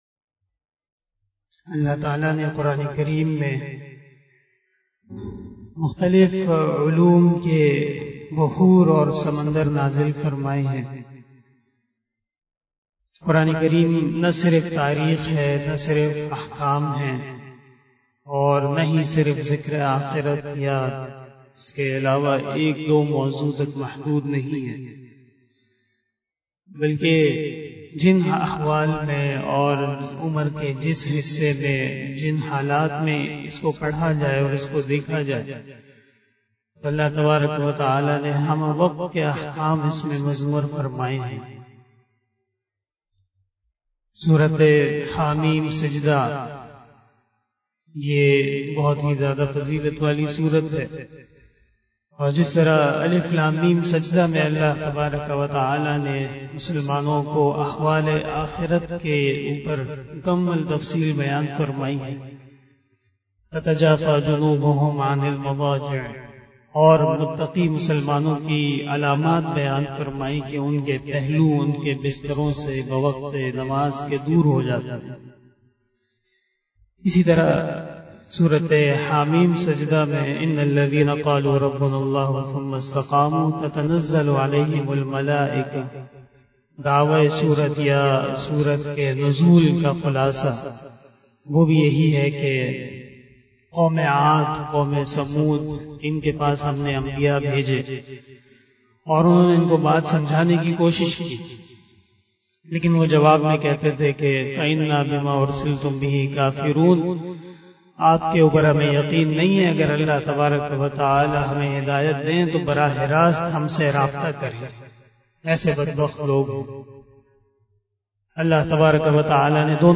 047 After Isha Namaz Bayan 29 August 2021 (20 Muharram 1443HJ) Sunday